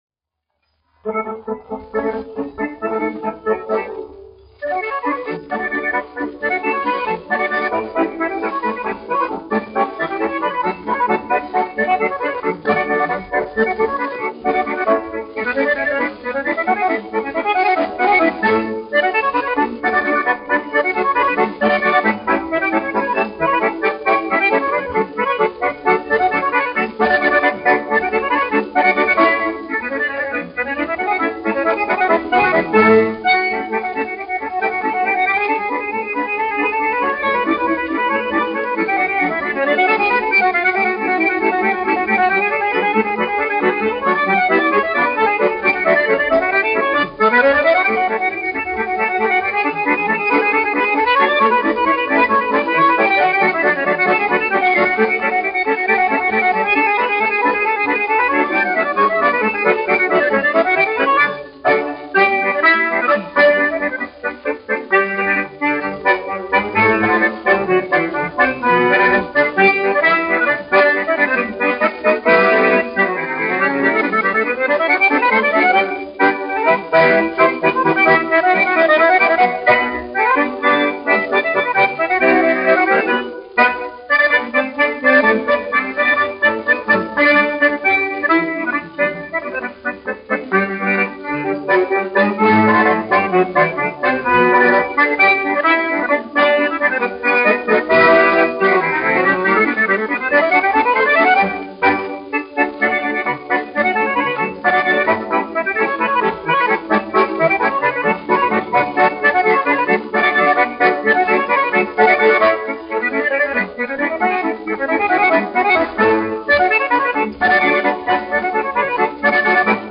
1 skpl. : analogs, 78 apgr/min, mono ; 25 cm
Polkas
Akordeona mūzika
Skaņuplate